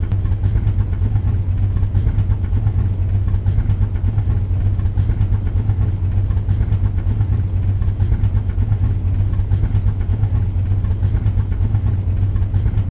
Index of /server/sound/vehicles/lwcars/dodge_daytona
idle.wav